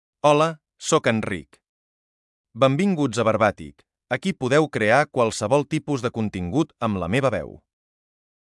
Enric — Male Catalan AI voice
Enric is a male AI voice for Catalan.
Voice sample
Listen to Enric's male Catalan voice.
Male
Enric delivers clear pronunciation with authentic Catalan intonation, making your content sound professionally produced.